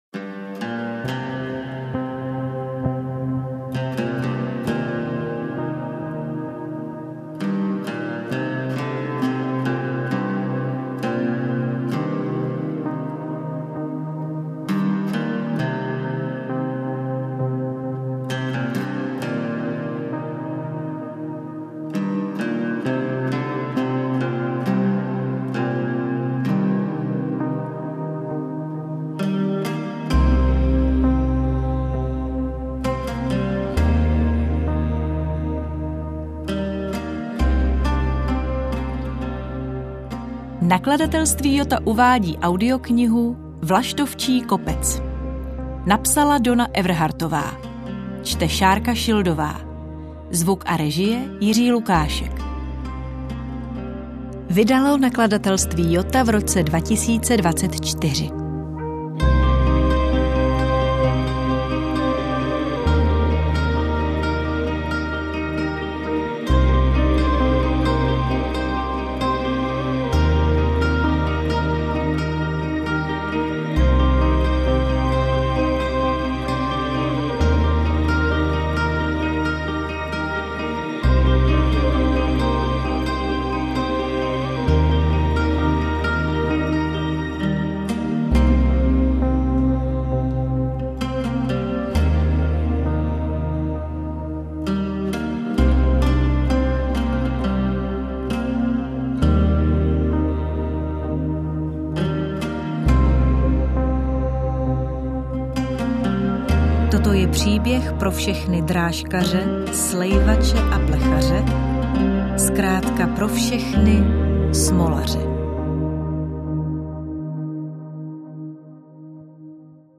AudioKniha ke stažení, 37 x mp3, délka 13 hod. 33 min., velikost 742,3 MB, česky